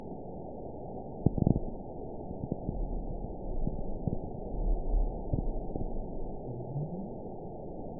event 922225 date 12/28/24 time 12:33:38 GMT (11 months, 1 week ago) score 9.56 location TSS-AB04 detected by nrw target species NRW annotations +NRW Spectrogram: Frequency (kHz) vs. Time (s) audio not available .wav